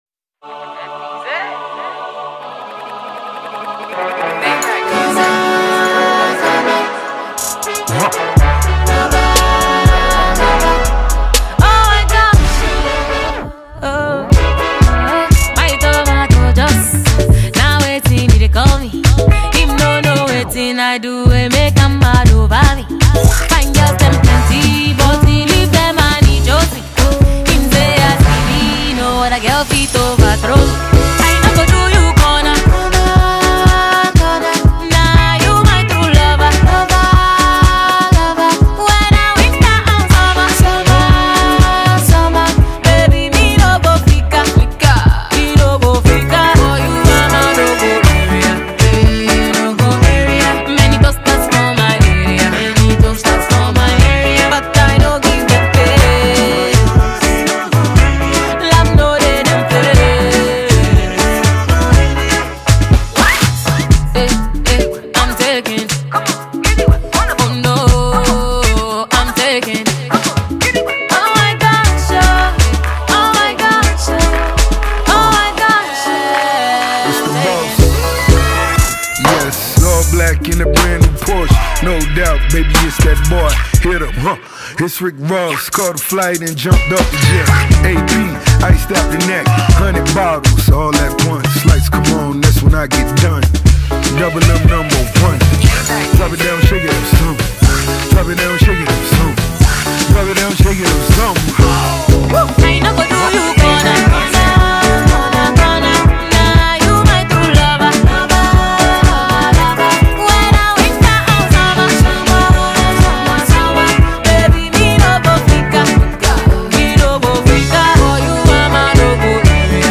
American rapper